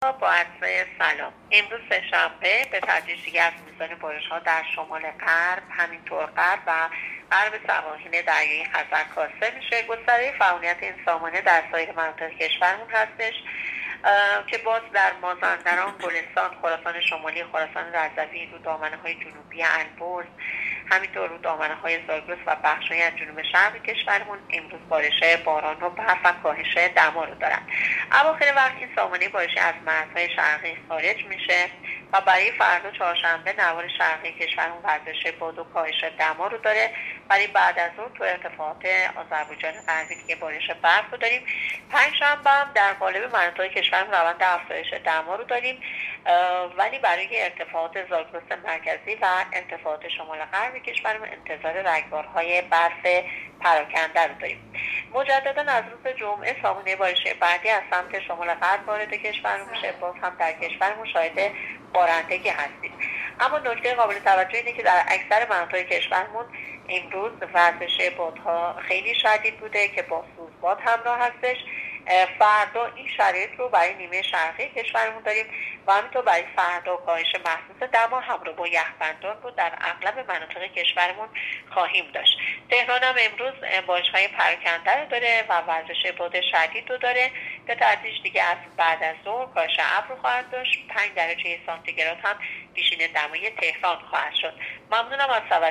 گزارش رادیو اینترنتی از آخرین وضعیت آب و هوای نهم دی؛